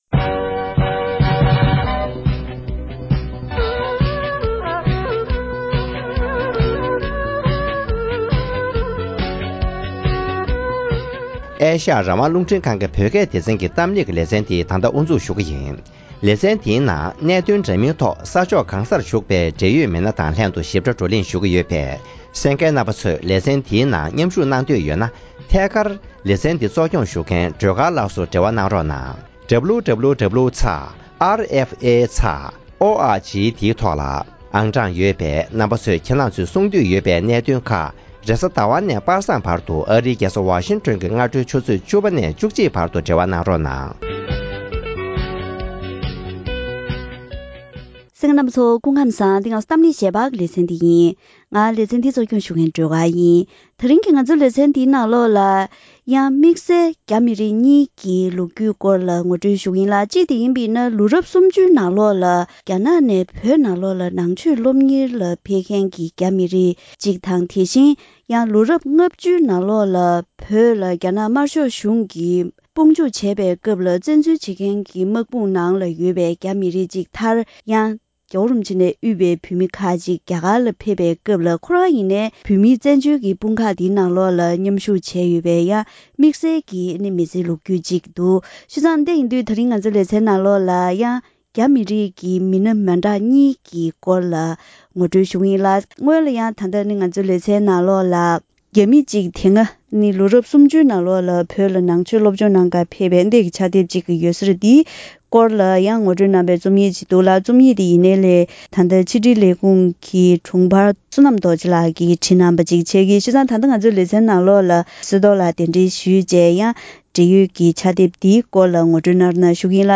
༄༅། །ཐེངས་འདིའི་གཏམ་གླེང་ཞལ་པར་ལེ་ཚན་ནང་།